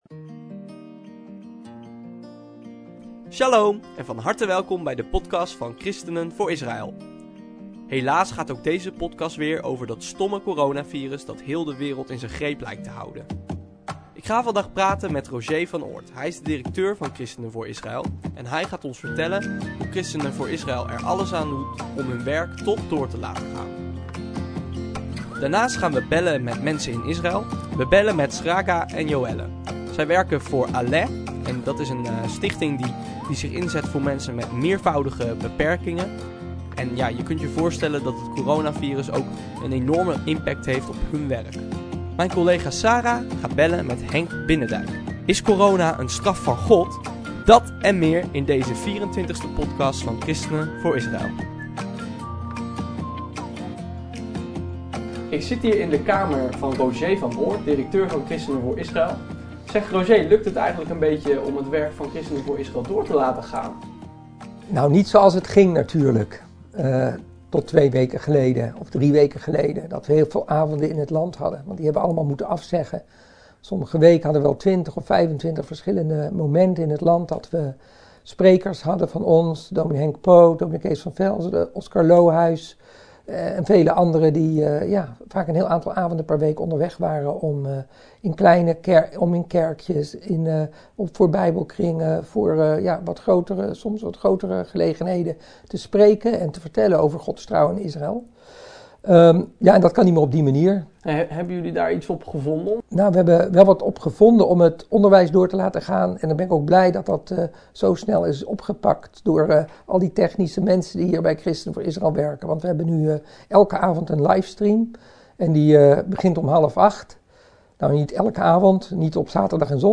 Ook bellen we met twee medewerkers van Aleh in Israël, want enkele kinderen hebben corona. Hoe gaat de organisatie voor meervoudig gehandicapte kinderen daarmee om? We nemen een kijkje in de Israëlwinkel.